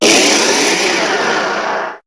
Cri de Méga-Alakazam dans Pokémon HOME.